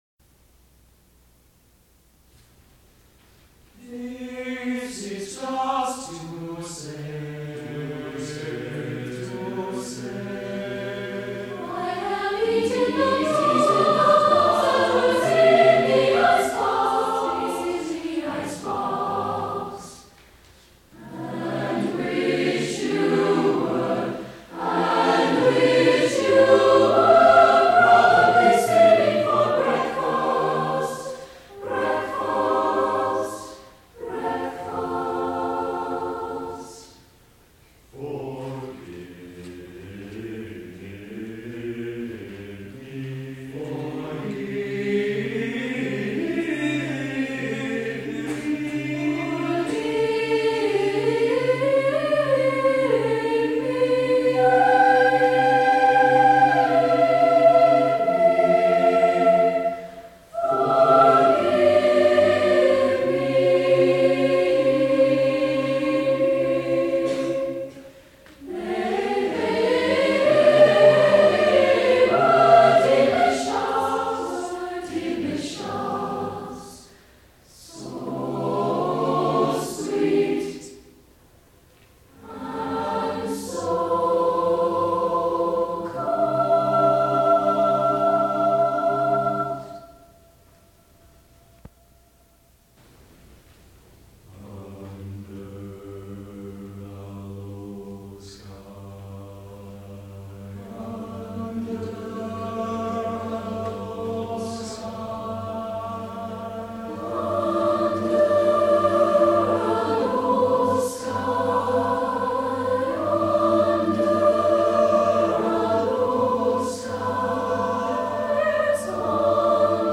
A choral setting of three
Faculty of Music Chorus